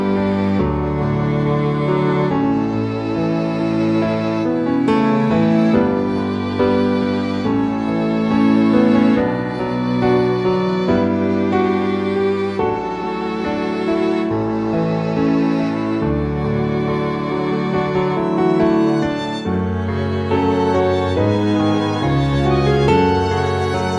Down 3 Semitones For Female